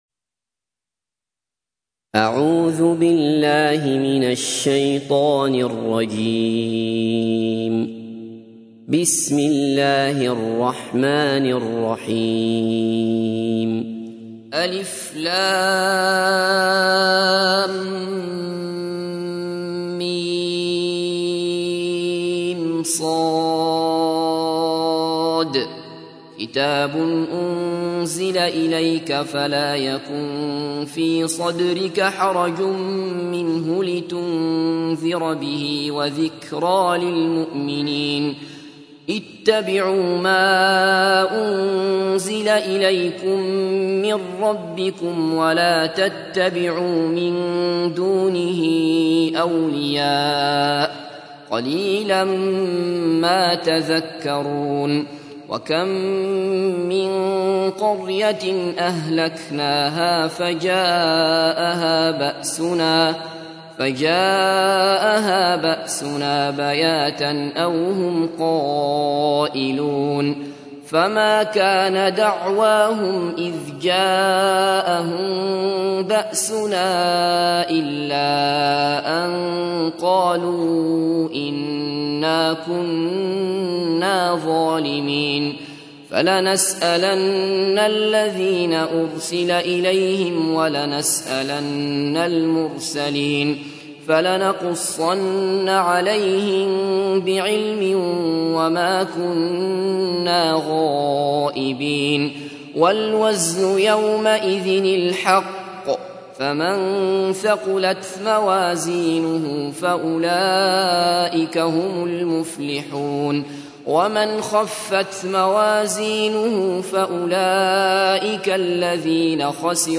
تحميل : 7. سورة الأعراف / القارئ عبد الله بصفر / القرآن الكريم / موقع يا حسين